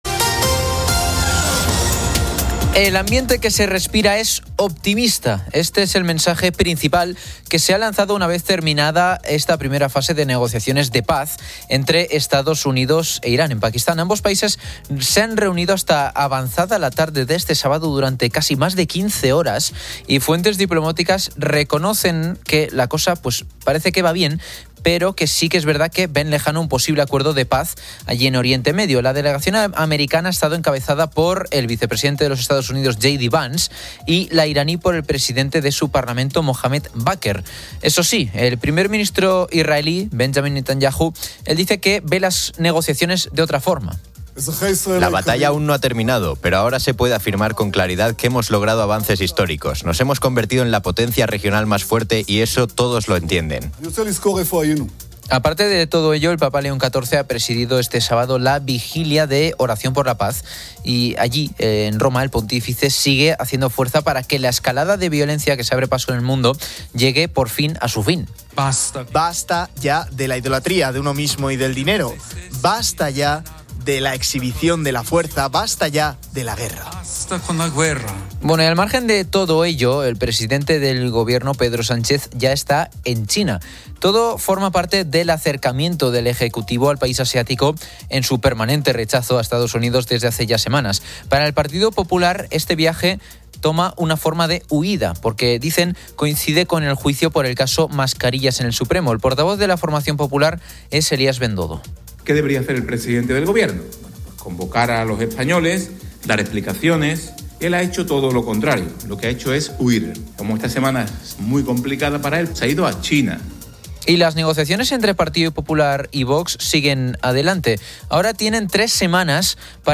El programa avisa sobre el uso de inteligencia artificial en sus contenidos y voces.